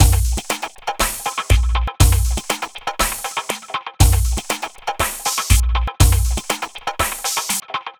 Astro 2 Drumz Dry.wav